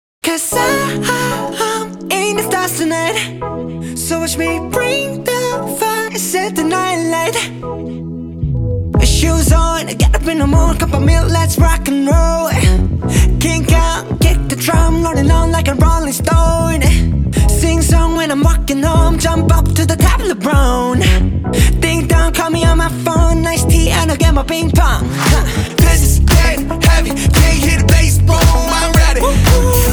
• K-Pop
It is the band's first song fully recorded in English.